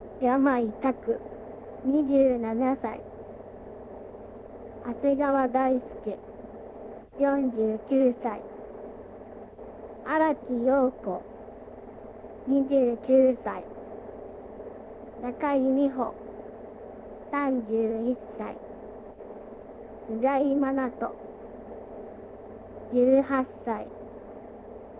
そんな部屋の中、どこからともなく声が流れてきました。
どういうわけか、ラジオの電源が勝手に入ります。
▲ゲーム内で突然流れる声。